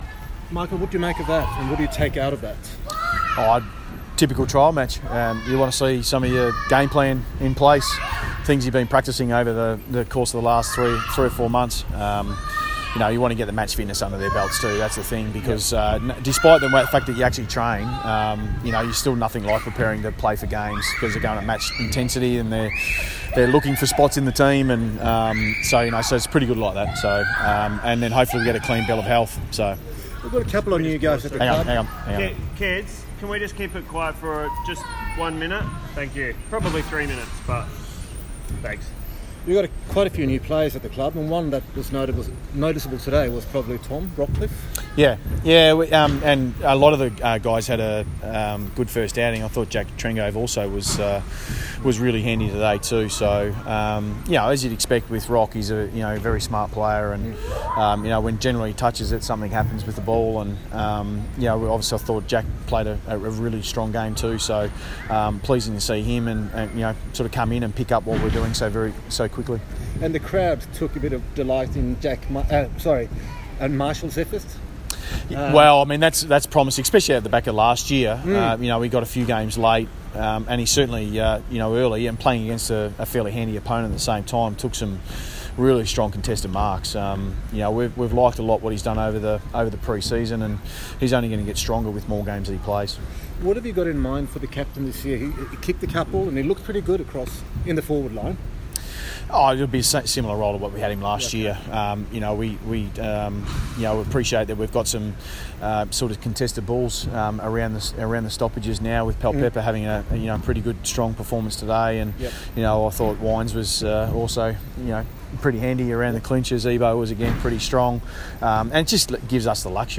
Michael Voss press conference after intra-club trial match - Saturday, 10 February, 2018
Michael Voss talks with media after Port Adelaide's annual Family Day intra-club trial match.